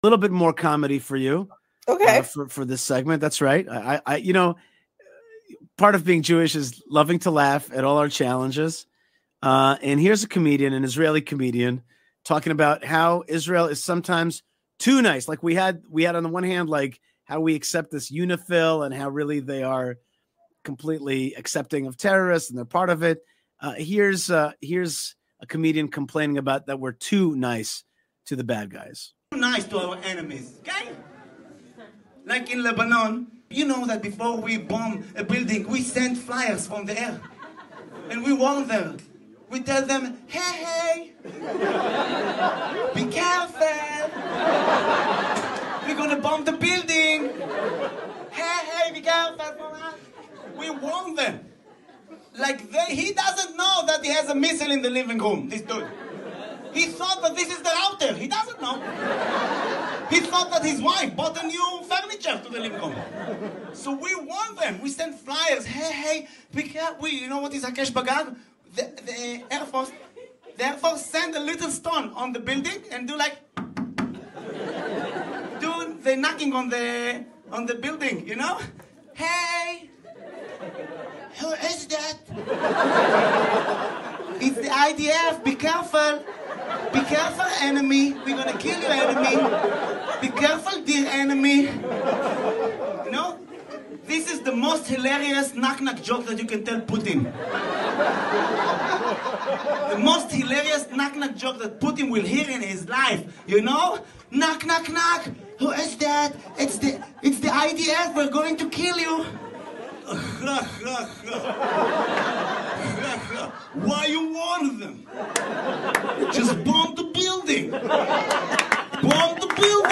Israeli Comedian Stuns Crowd: ''IDF IS TOO NICE TO ENEMIES''